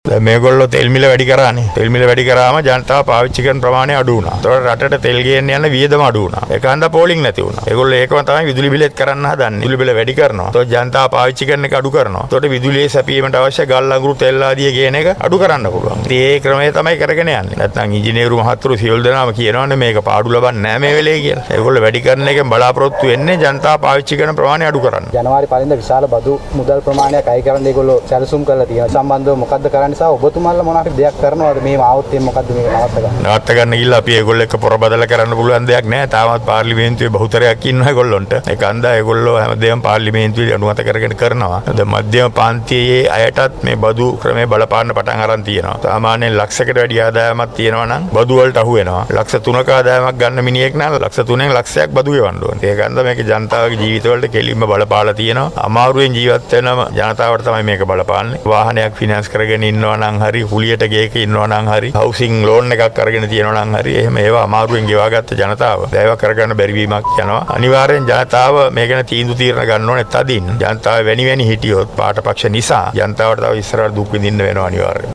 මන්ත්‍රීවරයා මෙම අදහස් පල කළේ කැලණිය වනවාසල ප්‍රදේශයේ පැවති වැඩසටහනකට එක්වීමෙන් අනතුරුව මාධ්‍ය වෙත අදහස් පල කරමින් .